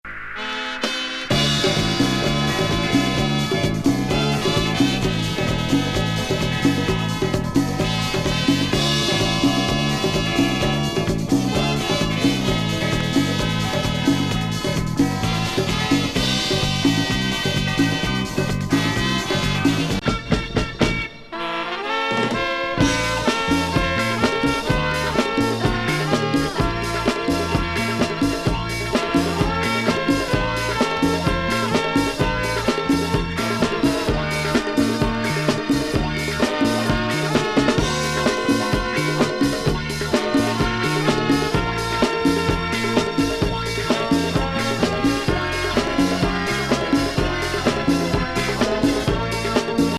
コンガ、ホーン、シンセの熱烈コズミック・ファンク